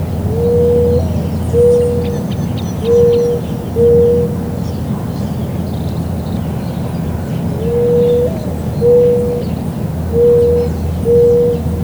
"Tórtola Cardosantera"
Zenaida aurita
tortola-cardosantera.wav